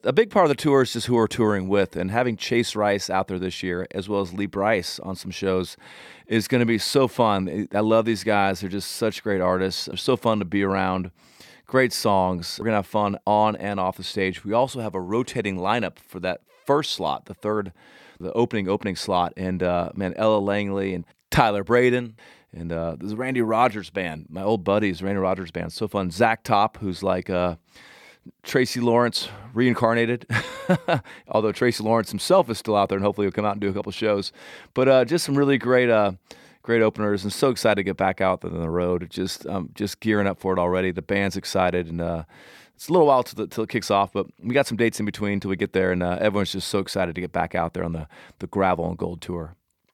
Dierks Bentley talks about some of the opening acts on his 2024 Gravel & Gold Tour.